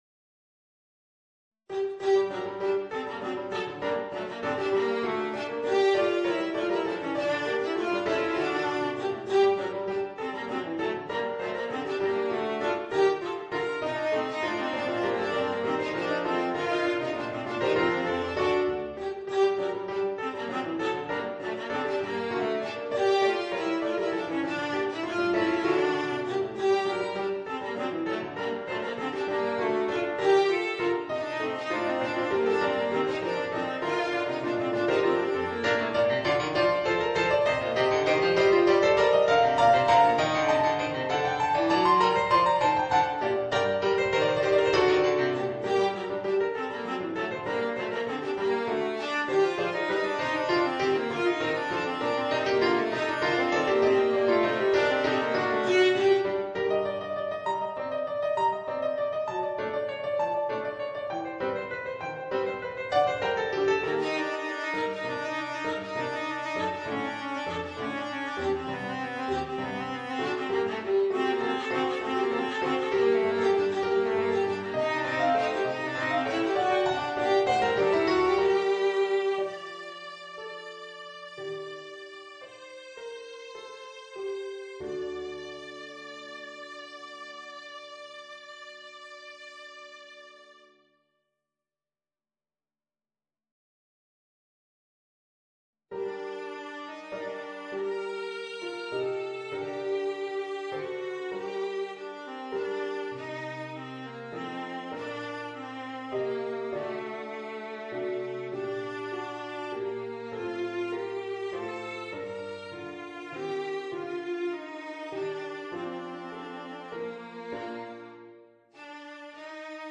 Voicing: Viola and Organ